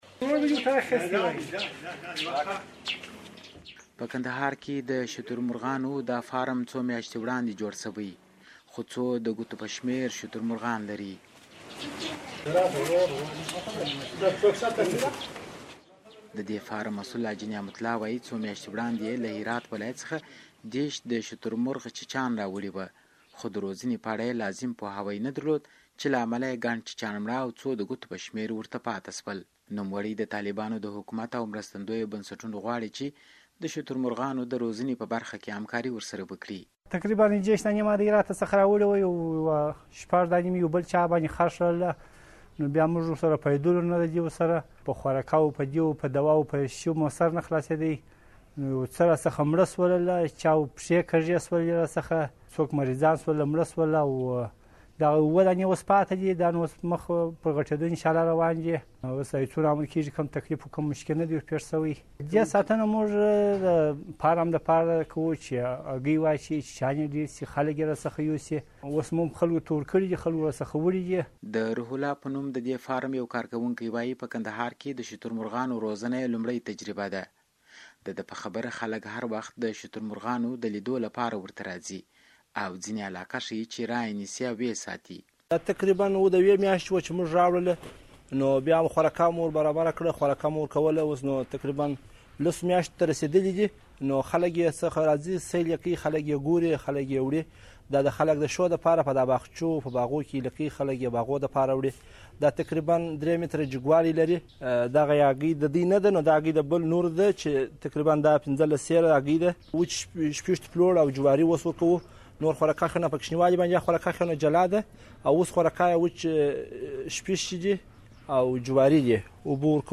په دې اړه زموږ د همکار راپور، د لاندې لینک له کېکاږلو سره اورېدلی شئ: